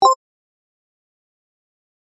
決定ボタン01 - 音アリー